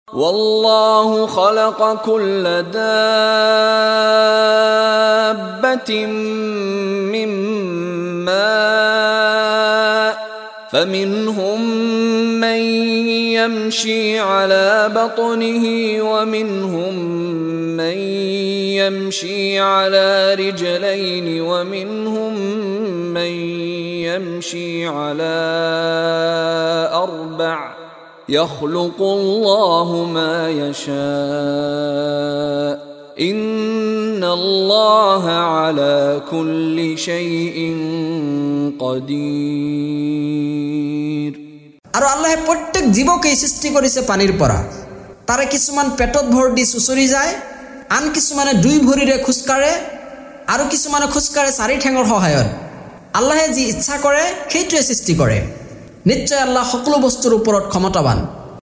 লগতে ক্বাৰী মিশ্বাৰী ৰাশ্বিদ আল-আফাছীৰ কণ্ঠত তিলাৱত।